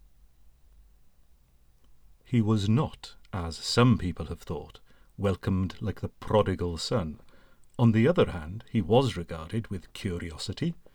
The room I’ve been using generally is our small spare room, used as a Library/box room/study, so he walls are lined with bookshelves and boxes, so seems not too echoey.